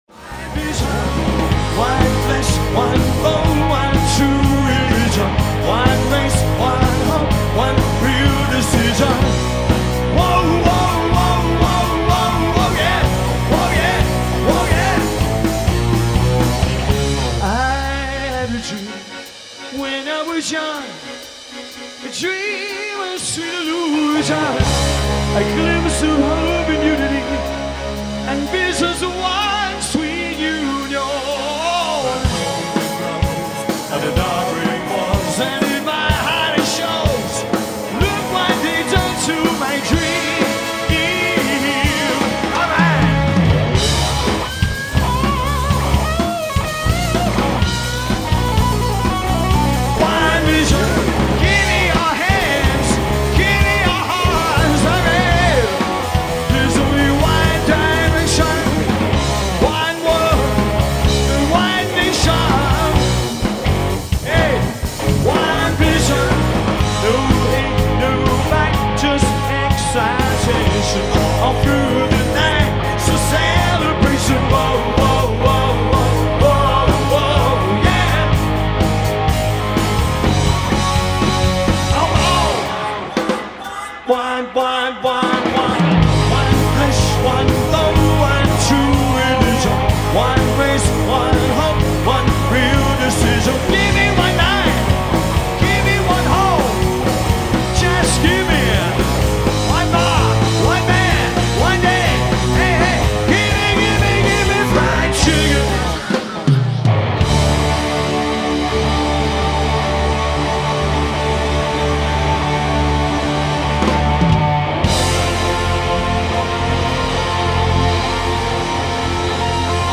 Band Live Audio-Recorded off The Mixer